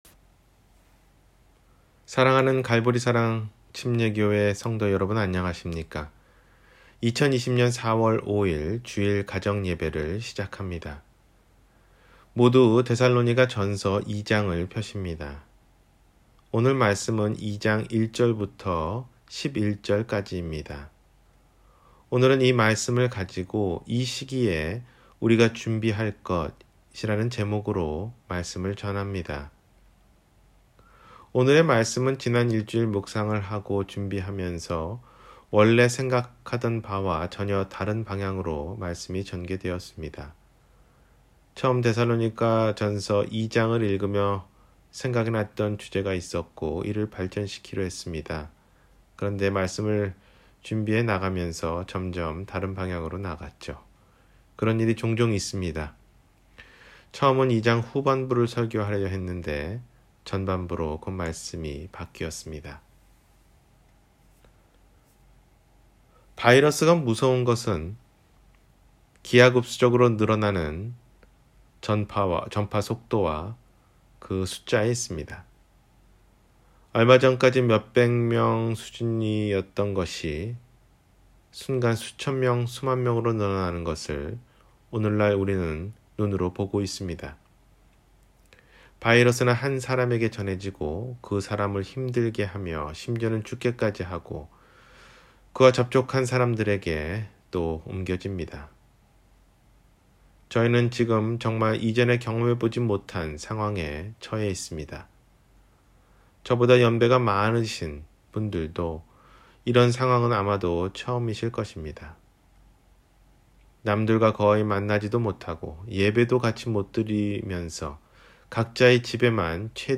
이 시기에 우리가 준비할 일 – 주일설교